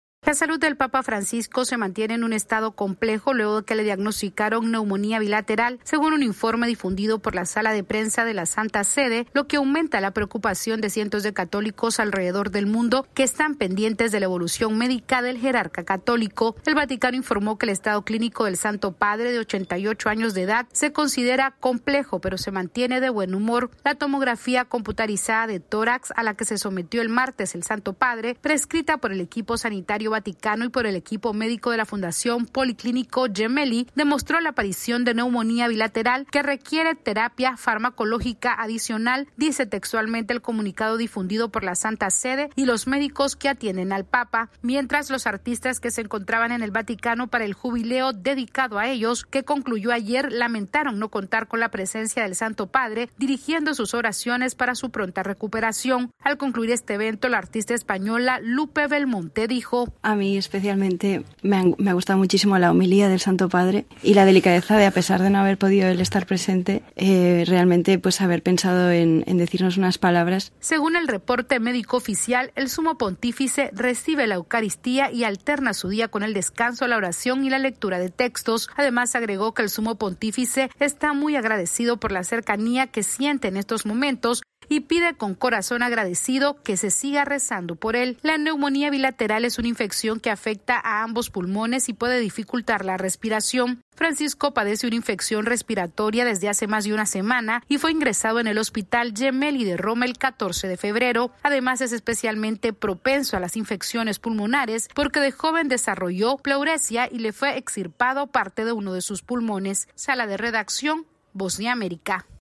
AudioNoticias
El papa Francisco amaneció en condición estable y los médicos del hospital Gemelli intensifican los tratamientos luego de confirmarse que tiene una neumonía bilateral con un cuadro complicado. Esta es una actualización de nuestra Sala de Redacción.